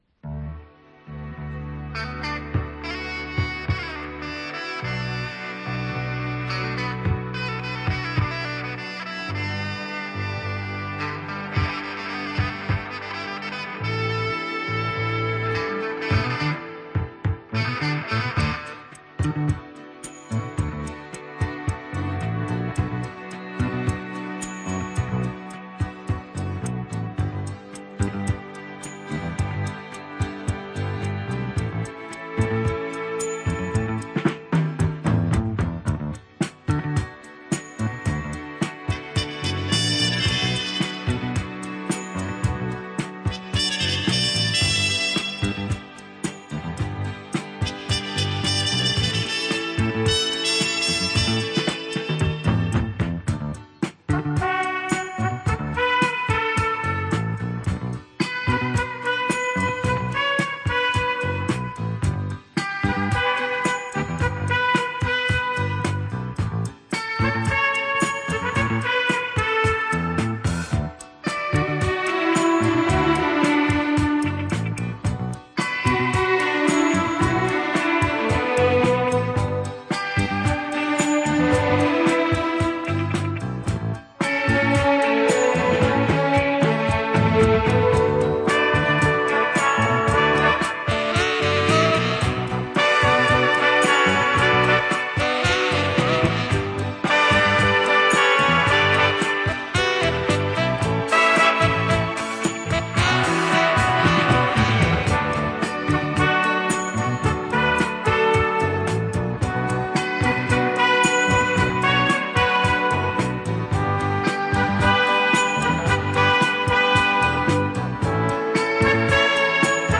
Жанр: Easy Listening
Носитель: LP